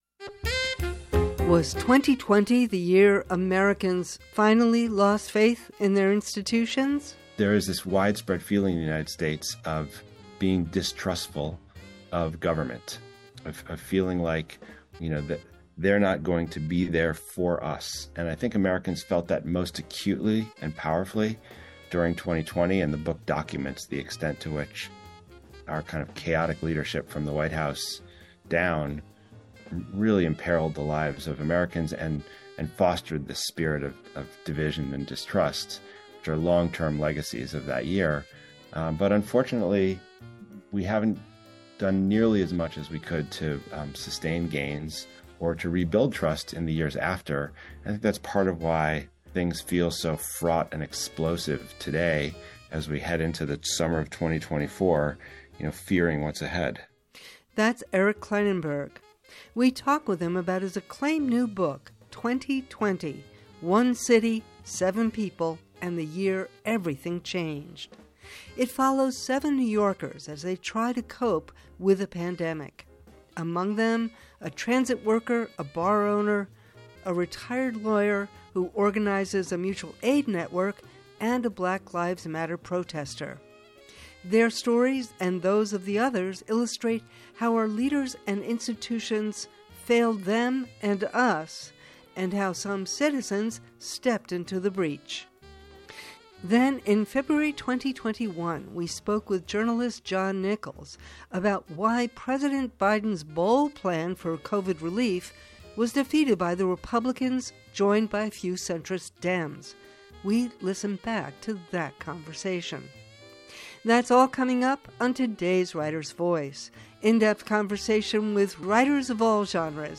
Was 2020 the year Americans finally lost faith in their government? We talk with Eric Klinenberg about his acclaimed new book, 2020: One City, Seven People, And The Year Everything Changed.
Then, in February 2021 we spoke with journalist John Nichols about why President Biden’s bold plan for Covid relief was defeated by the Republicans, joined by a few centrist Dems.
Writer’s Voice — in depth conversation with writers of all genres, on the air since 2004.